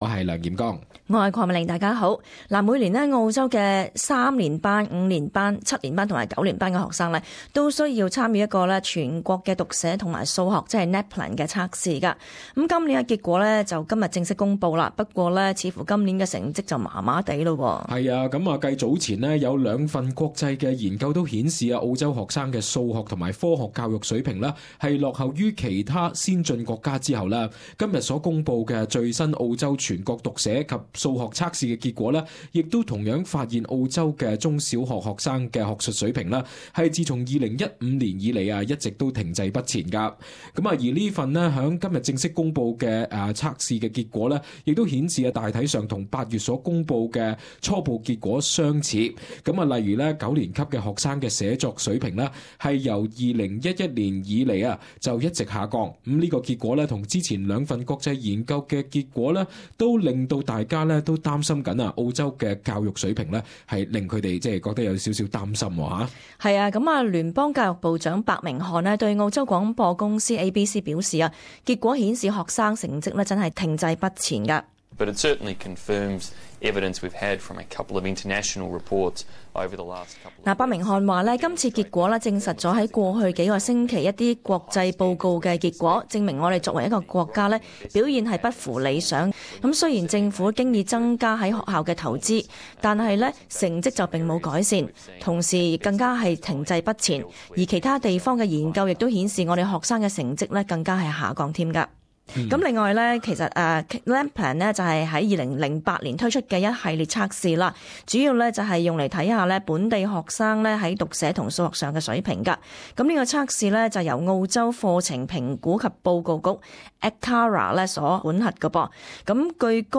【時事報導】 澳洲中小學生學術水平停滯不前